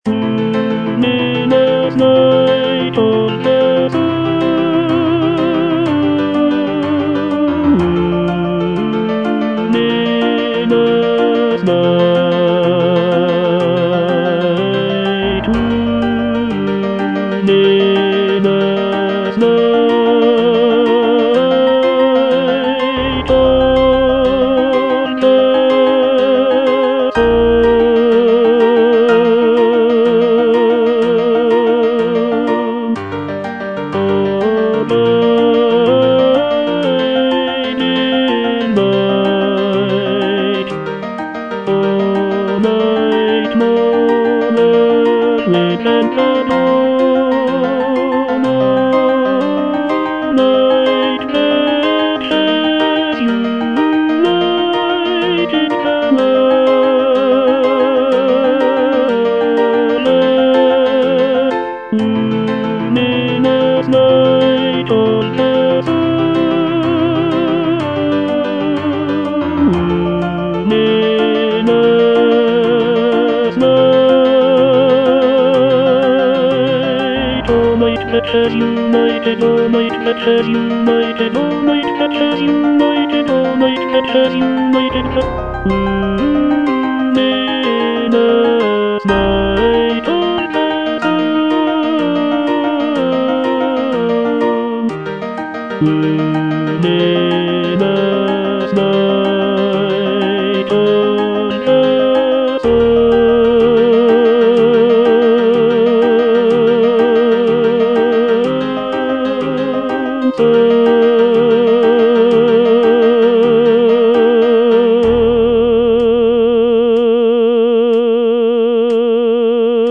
(tenor II) (Voice with metronome) Ads stop
a choral work